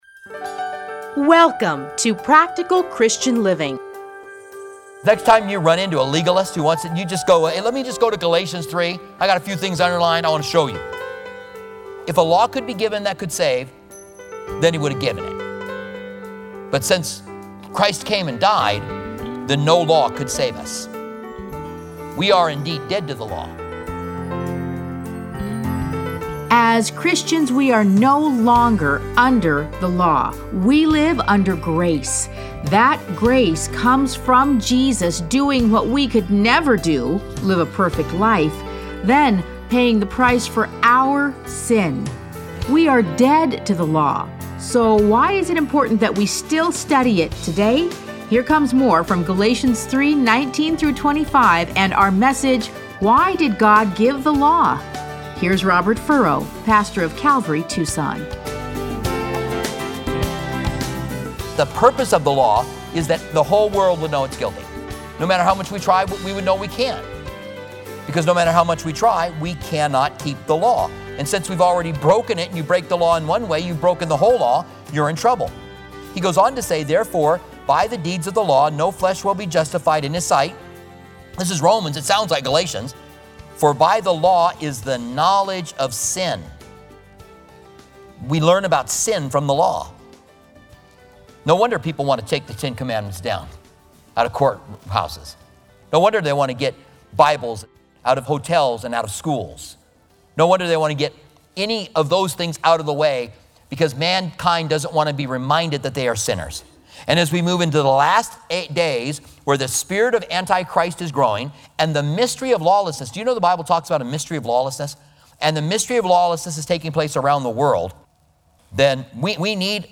Listen to a teaching from Galatians 3:19-25.